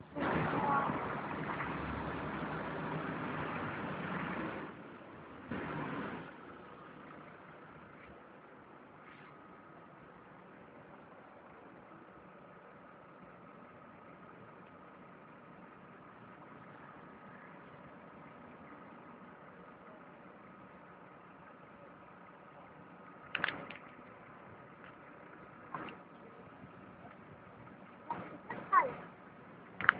Voitures qui passent